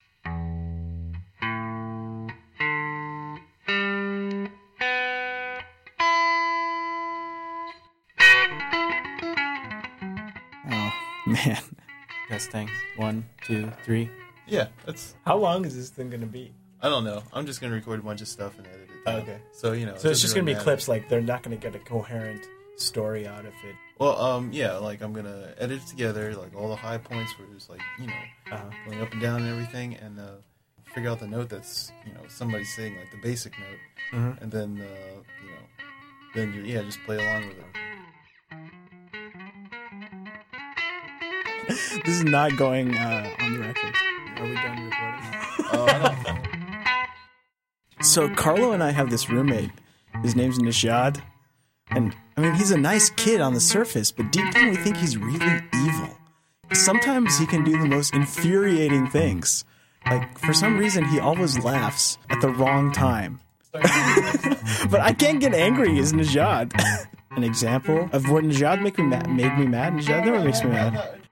After recording the guitar takes, it was just a matter of selecting the best guitar preformance takes, editing that together into a continuous piece and then composing the project into a whole composition.
Afterwards I just cleaned up the piece, adding some eq, delay and reverb to the guitar track, making the guitar sound larger, but also pushing it back into the mix and "brightend" the vocal track by pushing the high end frequencies up with eqing.
Overall I thought the project turned out well into terms of sound quality, but in comparison with the Dr. Deutsch piece, the melody of a whole conversation seems much more atonal and random, although still interesting.
Final Mix